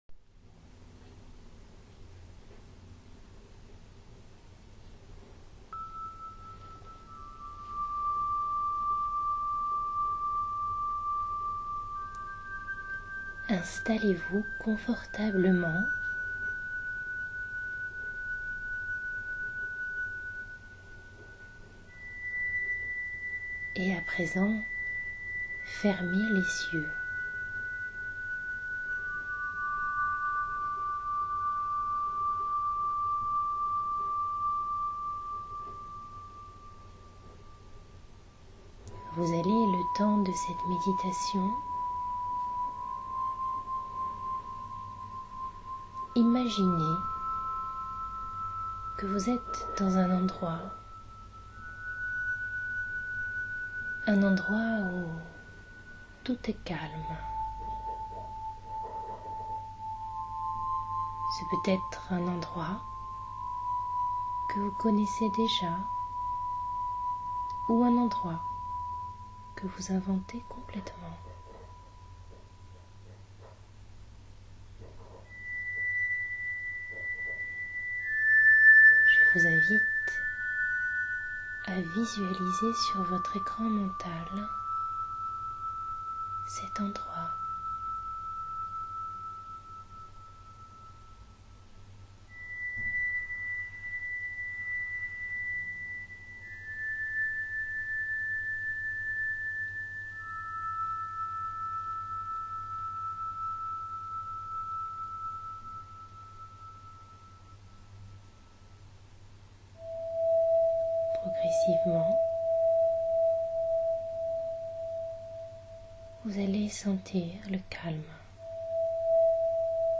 sophro-respirationguidee.mp3